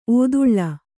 ♪ ōduḷḷa